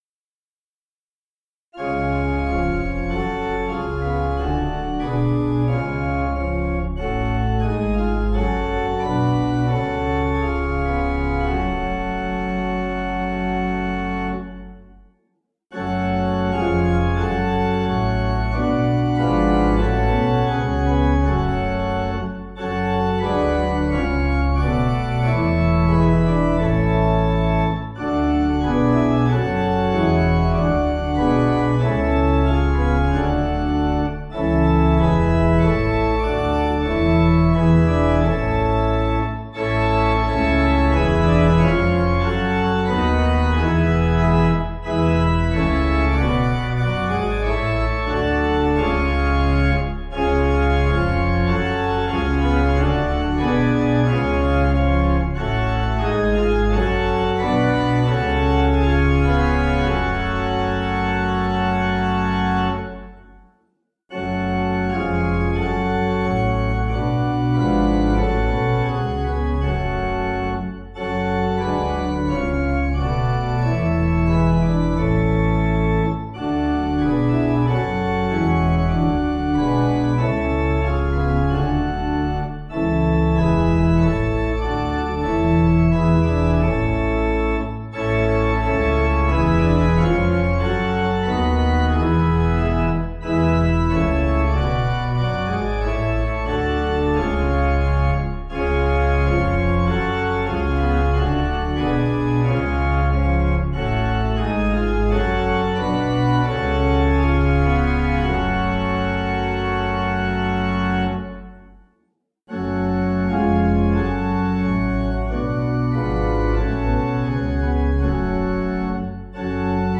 Organ
(CM)   4/F#m